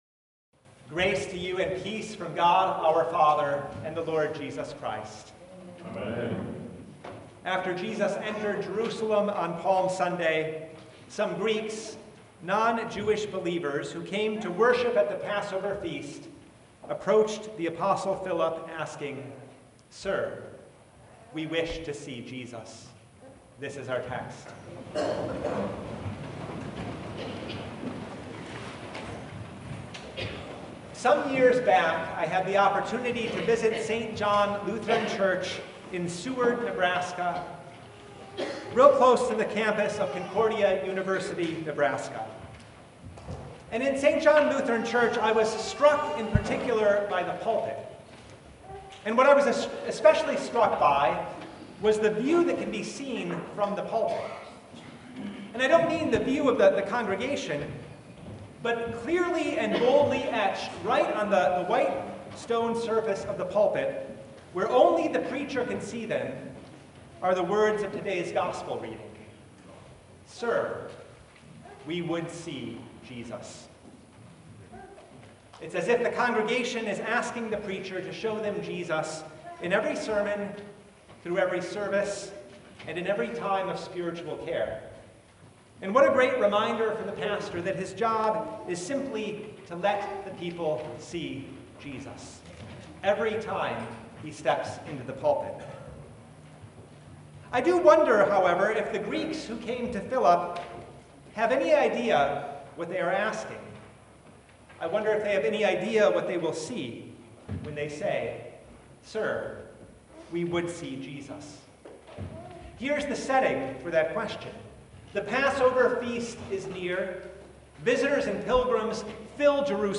Download Files Notes Topics: Sermon Only Resurrection Sunday – Finding the Unexpected, Just as He Said »